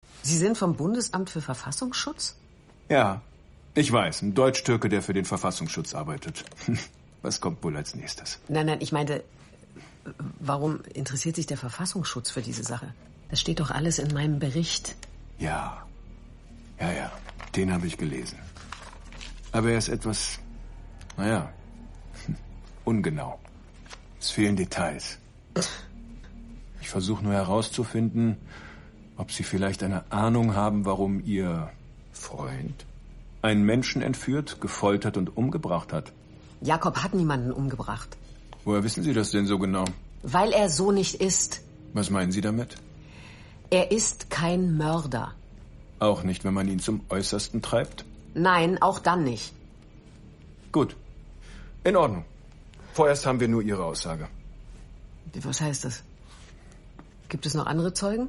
sehr variabel, markant
Mittel plus (35-65)
Audio Drama (Hörspiel)